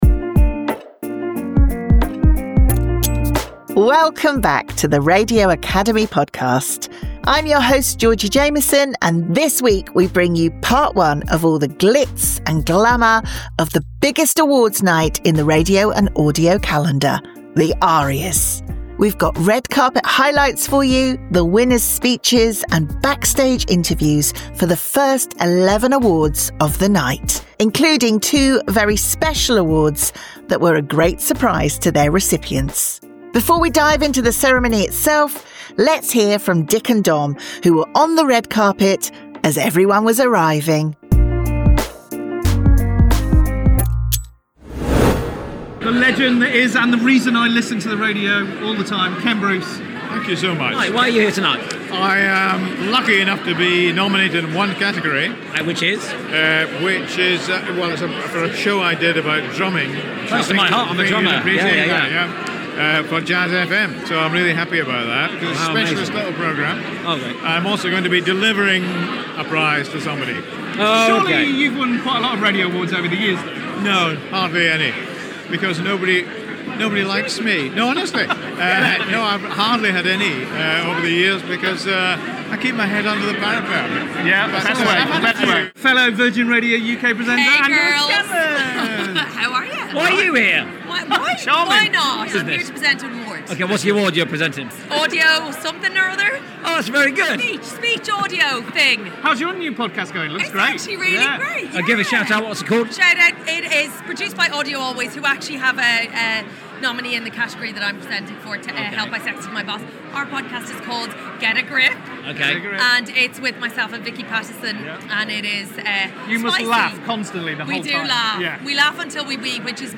Headliner Embed Embed code See more options Share Facebook X Subscribe This week we have part 1 of our Arias Award night roundup with highlights from the red carpet, winners speeches and backstage interviews. We’re featuring the first 11 awards of the night including Best New Presenter, Best Music Breakfast Show and Best Comedy Award, along with the surprise Special Recognition Award and the 2025 Judges' Award.